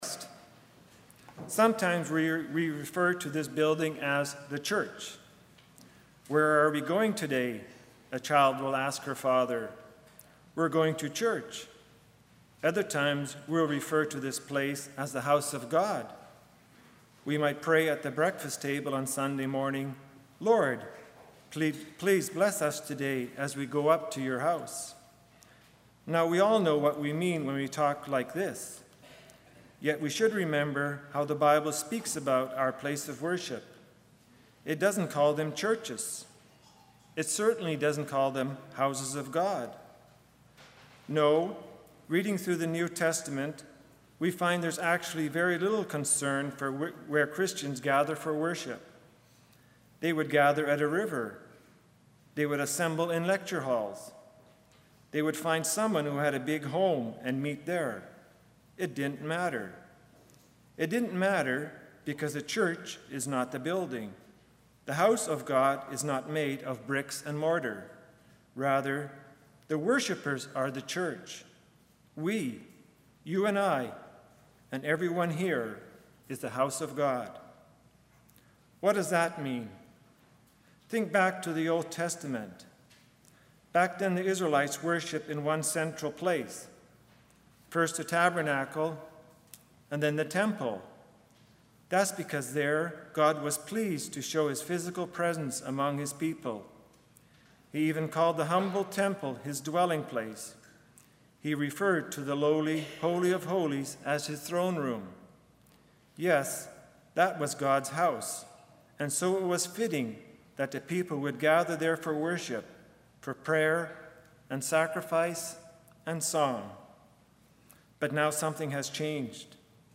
Ministry of the Word in 1 Peter 2:4-8
Service Type: Sunday Morning